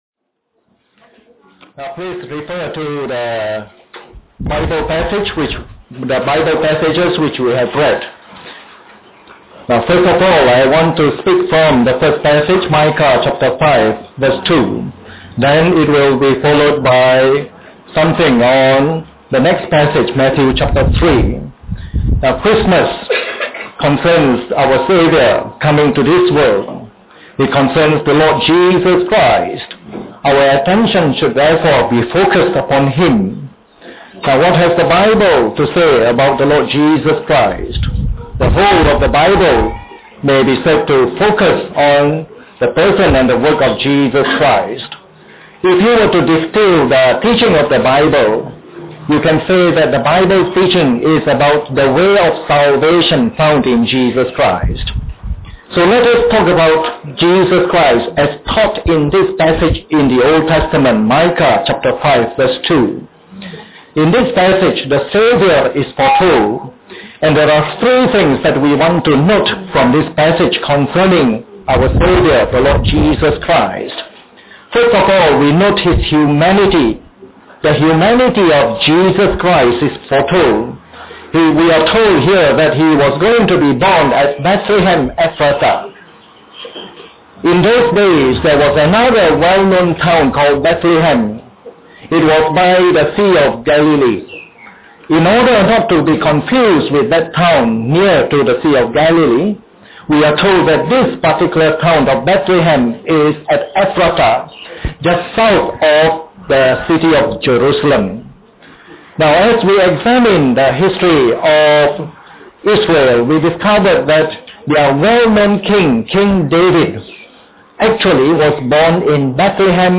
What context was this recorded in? This message was preached on Christmas Day during our yearly Christmas service.